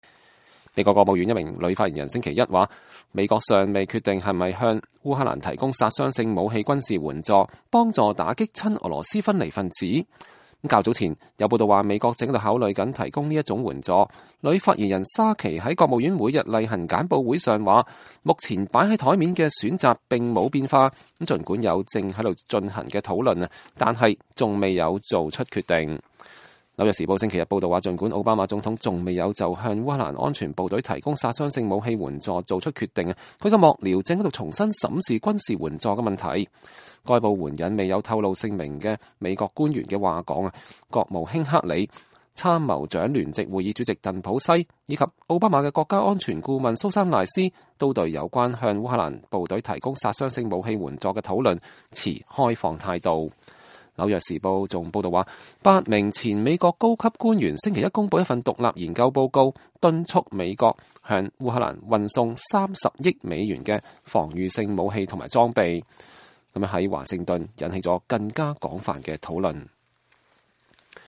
美國國務院女發言人莎琪